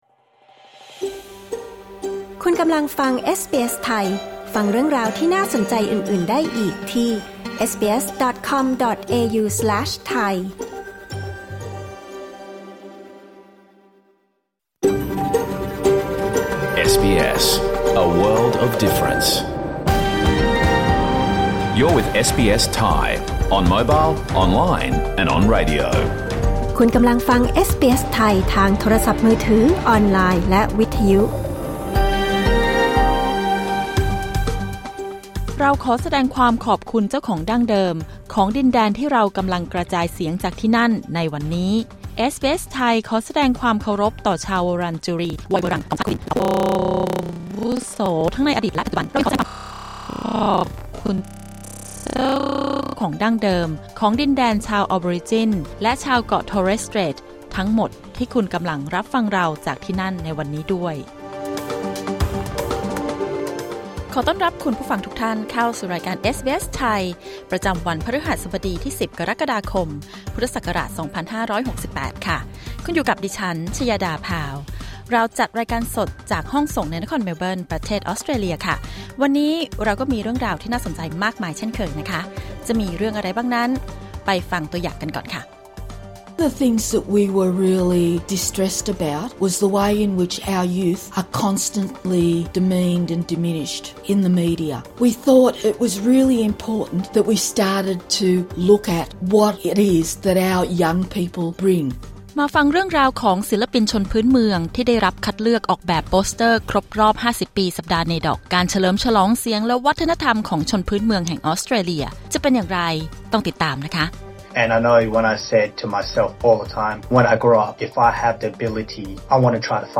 รายการสด 10 กรกฎาคม 2568